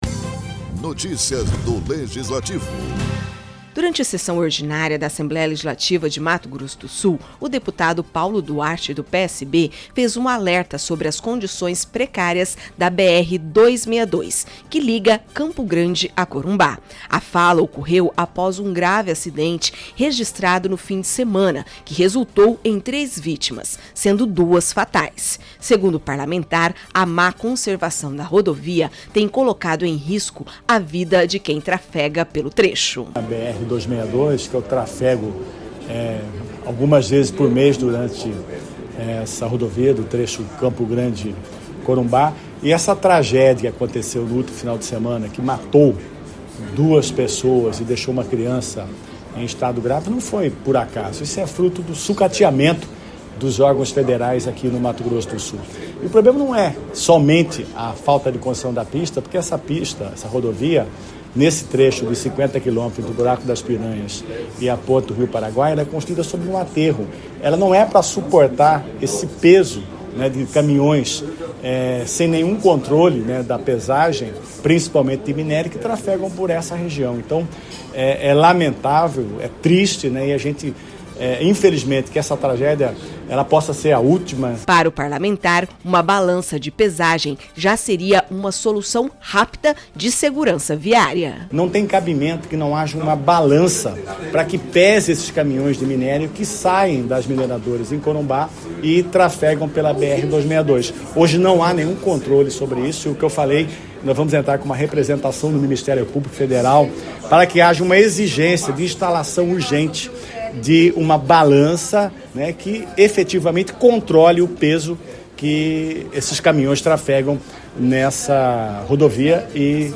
O grave acidente registrado no fim de semana na BR-262, entre Campo Grande e Corumbá, reacendeu o alerta sobre as condições precárias da rodovia. Durante sessão na Assembleia Legislativa de Mato Grosso do Sul (ALEMS), o deputado Paulo Duarte (PSB), cobrou ações imediatas para garantir a segurança dos motoristas e informou que vai acionar o Ministério Público Federal para investigar possíveis responsabilidades.